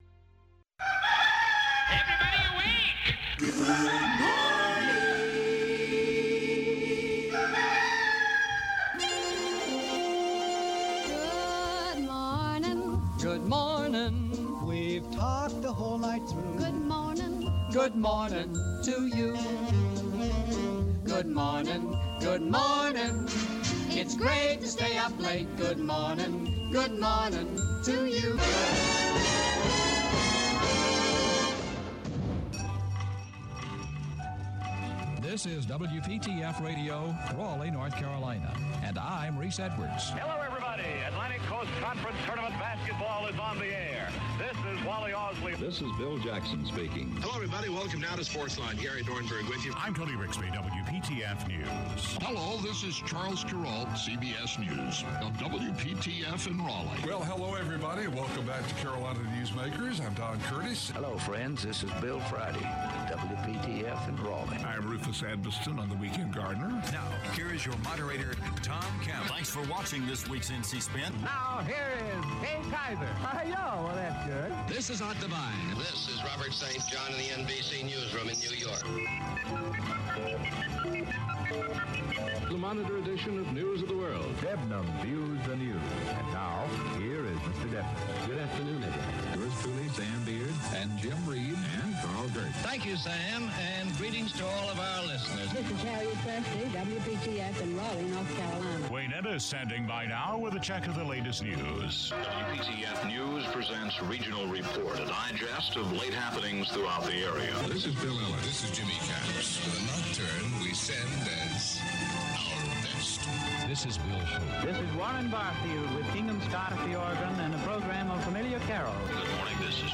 The WPTF Weekend Gardener is an award-winning radio talk show that has been on the air for over 30 years.